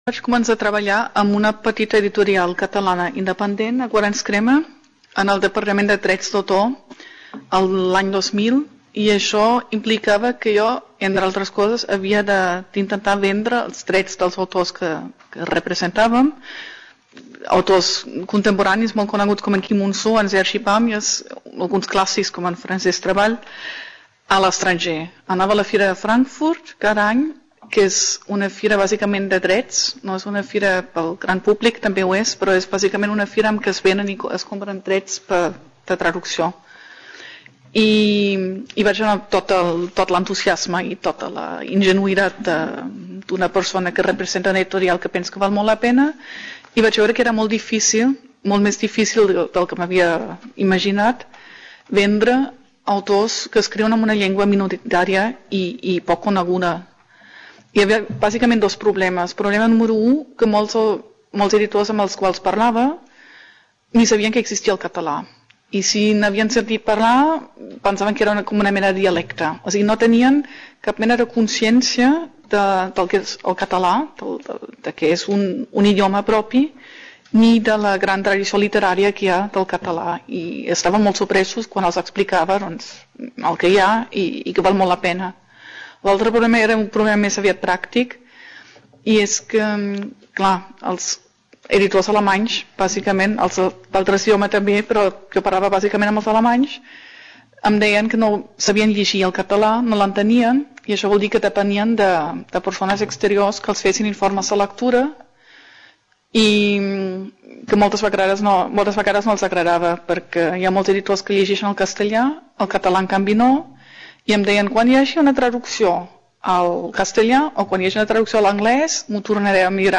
Reunion, debate, coloquio...